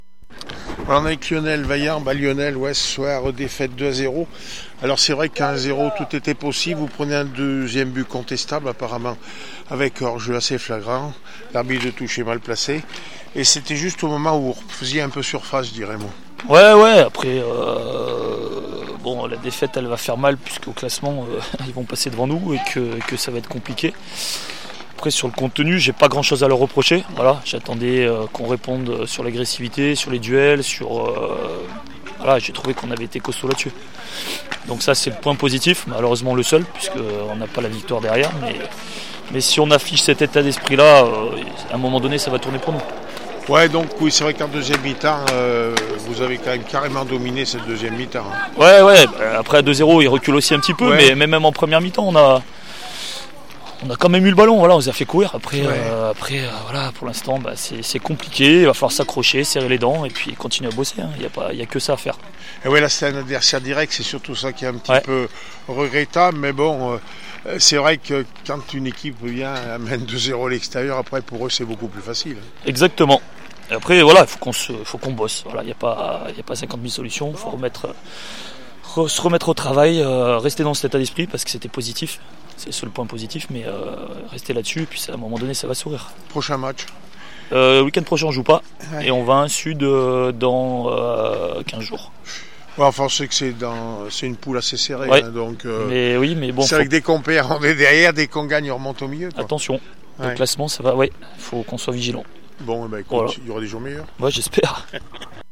nationale 3 foot aura le puy foot 43 0-2 chambery réac après match 240220